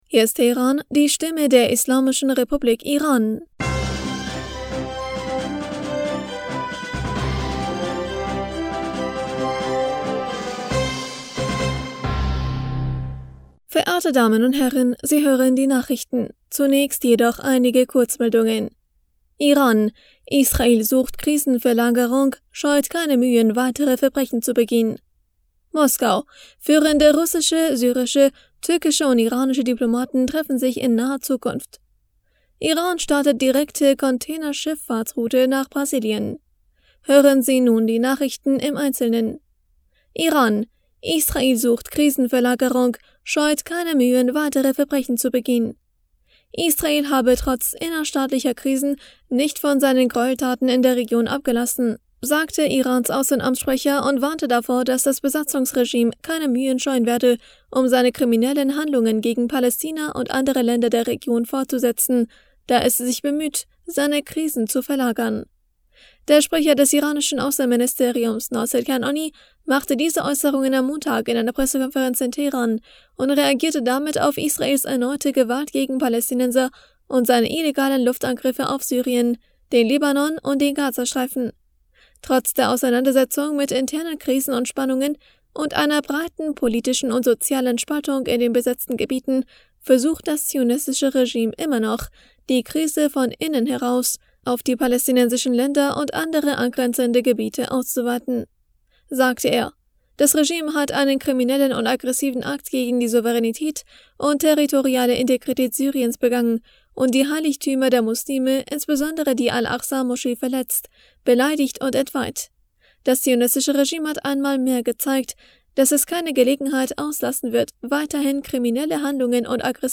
Nachrichten vom 11. April 2023